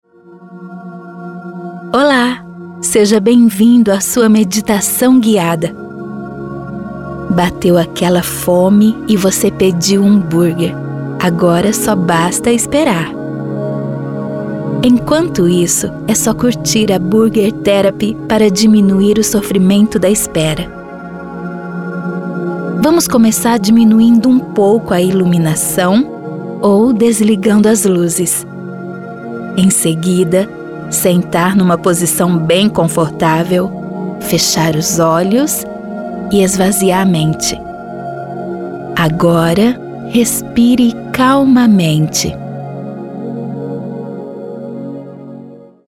Feminino
Propaganda Criativa, Meditação, Terapia
Tenho voz jovem, natural, facilidade para interpretação, agilidade na entrega do trabalho e bons equipamentos.